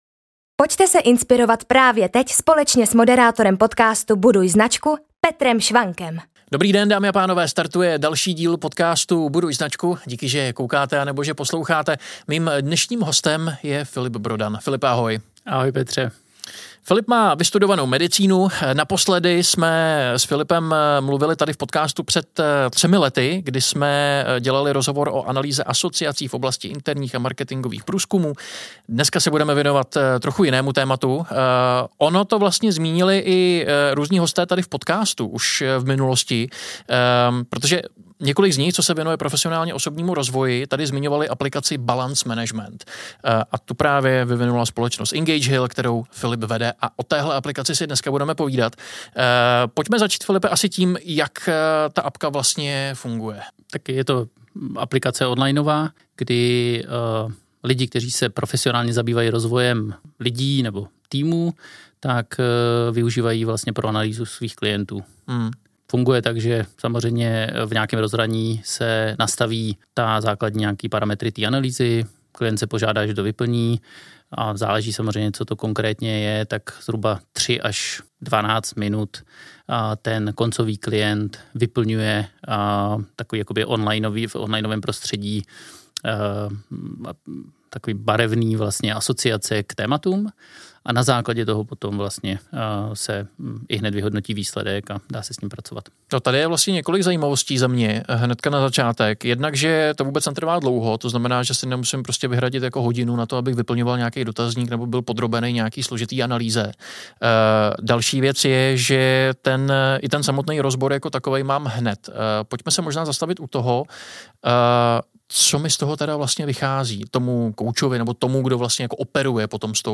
V dnešním rozhovoru diskutujeme o české aplikaci Balance Management , která dnes funguje již více než ve 20 světových jazycích. Balance Management zkvalitňuje práci sportovních i firemních koučů, terapeutů, mentorů a dalších profesionálů v oblasti rozvoje dospělých i dětí.